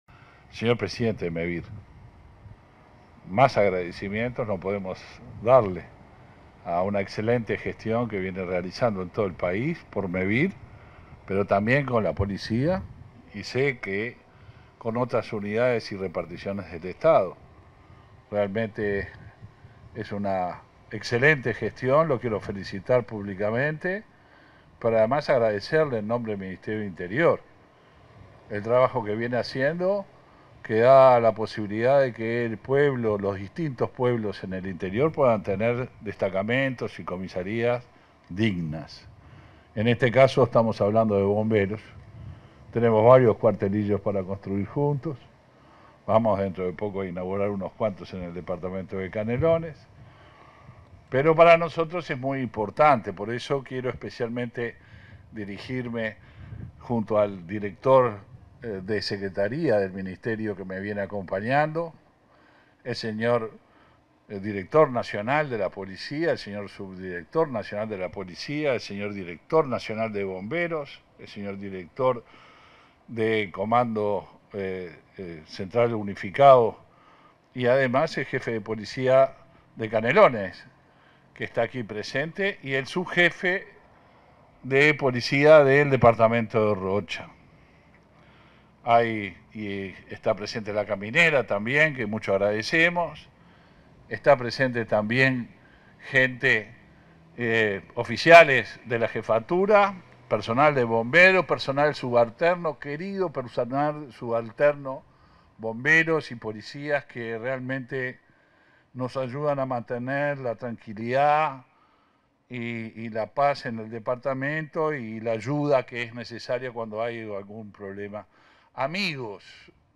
Palabras del ministro del Interior, Luis Alberto Heber
Palabras del ministro del Interior, Luis Alberto Heber 29/03/2023 Compartir Facebook X Copiar enlace WhatsApp LinkedIn En el marco de la firma de un convenio para instalar cámaras de videovigilancia en Canelones y Rocha, y la inauguración de 10 sitios de control en distintos balnearios de Rocha y un destacamento de Bomberos en Punta del Diablo, este 29 de marzo, se expresó el ministro del Interior, Luis Alberto Heber.